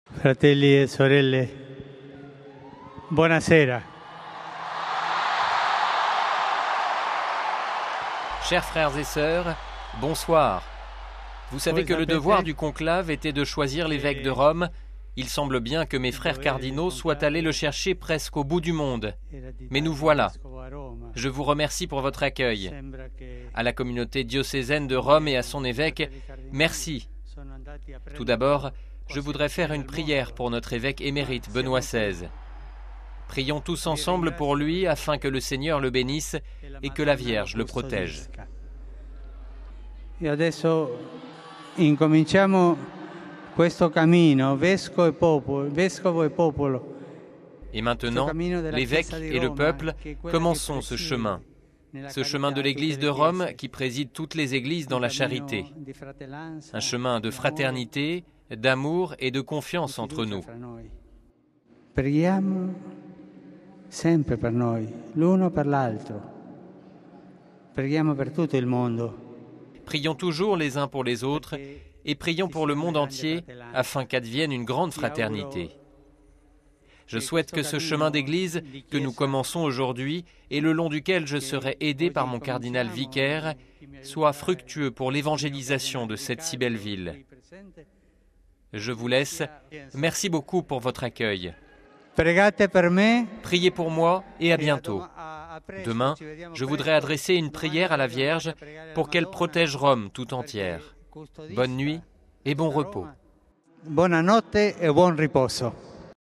C'est par ces deux mots tout simples, et si appropriés, puisque c'était le soir, que Jorge Bergoglio s'est adressé à la foule, il y a un an, le 13 mars 2013, depuis la loggia des bénédictions de la Basilique Saint-Pierre. Un bonsoir accueilli aussitôt par des cris de joie et et un tonnerre d'applaudissements.